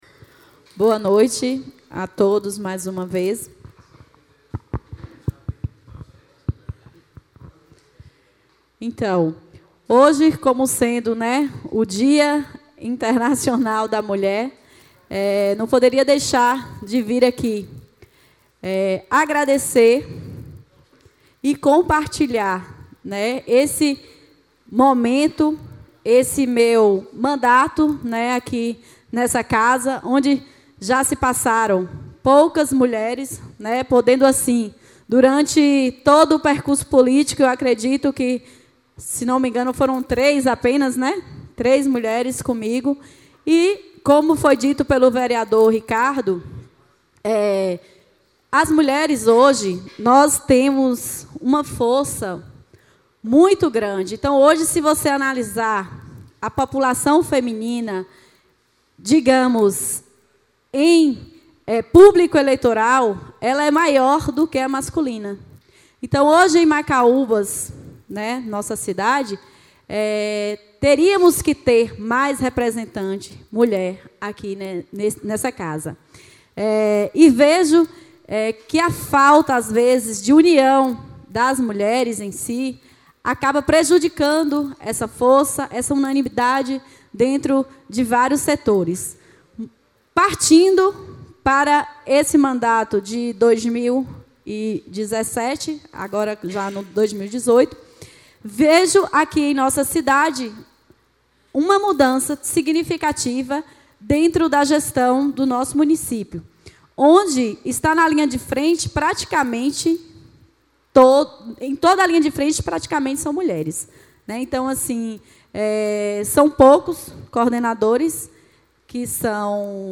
A 13ª Sessão Ordinária do Primeiro Período Legislativo da Legislatura 2017-2020 da Câmara Municipal de Macaúbas foi realizada às 19h00min, no Plenário da Casa da Cidadania.
A vereadora Márcia Brenda usou Tribuna Popular no grande expediente e além de destacar a importância da mulher em todos os setores, fazendo uma homenagem á todas as mulheres do município.